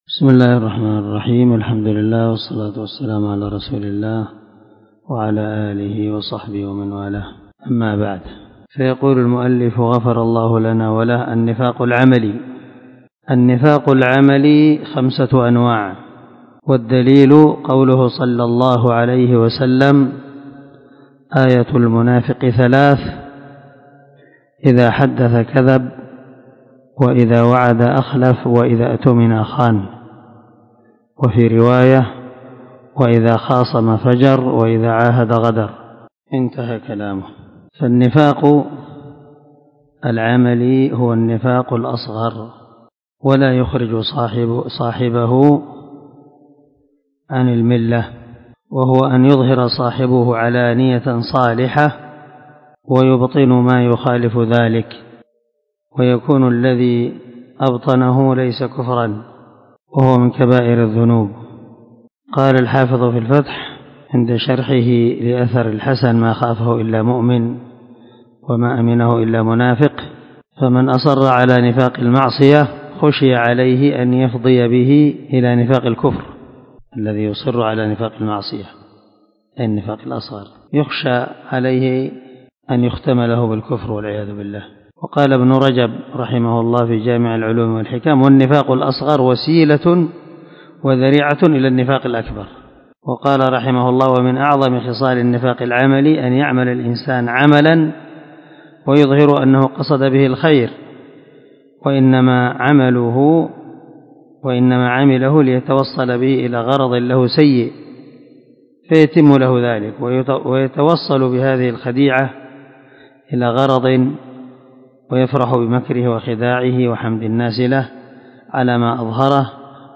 🔊الدرس 41 النفاق العملي ( من شرح الواجبات المتحتمات)
الدرس-41-النفاق-العملي.mp3